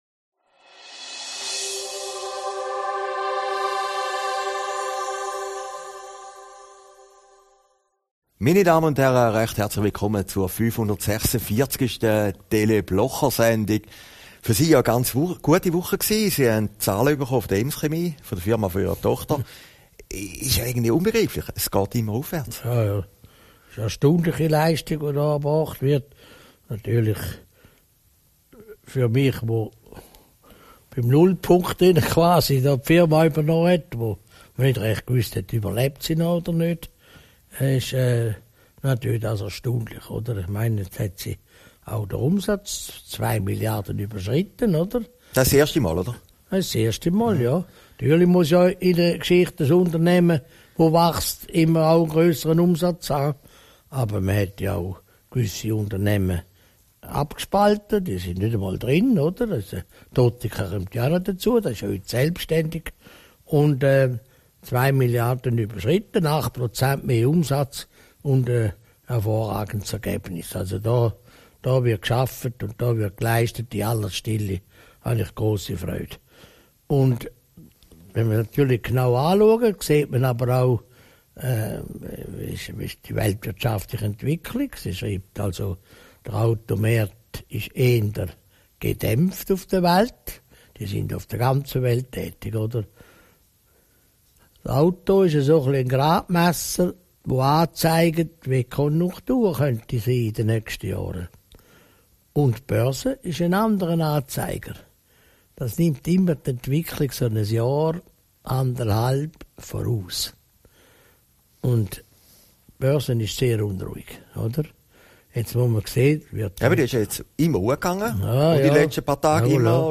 Aufgezeichnet in Herrliberg, 16. Februar 2018